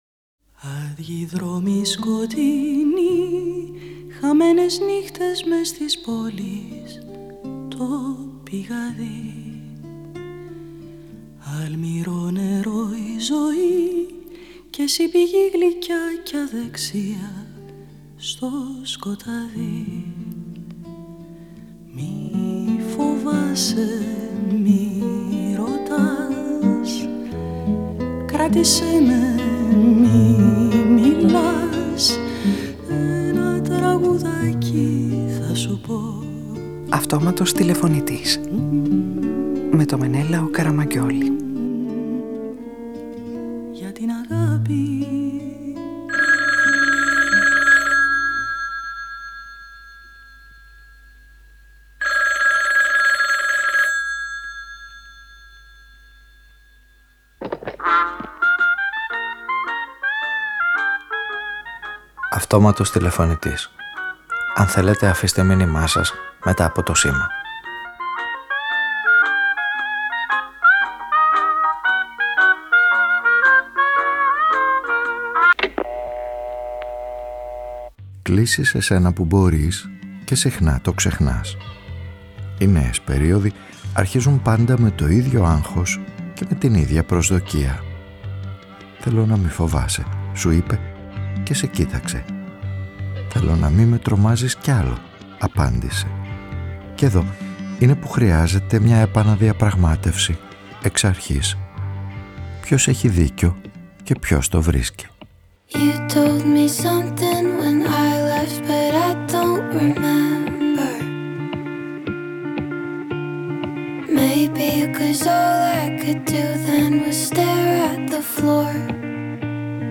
Ο ήρωας της σημερινής ραδιοφωνικής ταινίας παρακολουθεί το ατέρμονο κυνηγητό των ανθρώπινων σχέσεων που διεκδικούν σταθερότητα, πίστη και ελευθερία και μπλέκεται σε μια ιστορία υποσχέσεων: στο φινάλε της ιστορίας -με τη βοήθεια των Μπρεχτ, Ράινχαρτ, Σέξπιρ, Χειμωνά και Σαμφόρ- θα μπορέσει να εντοπίσει τι είναι εφικτό και τι είναι πλάνη;